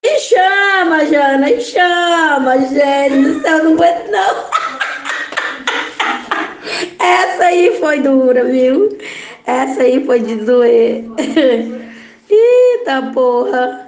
foi dura Meme Sound Effect